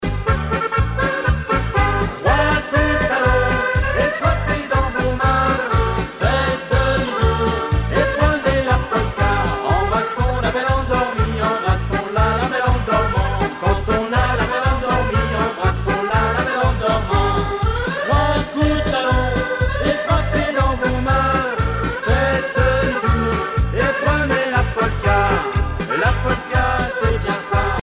Chants brefs - A danser
Pièce musicale éditée